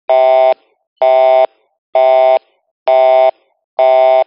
telephonebusy2.mp3